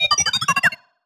sci-fi_driod_robot_emote_beeps_02.wav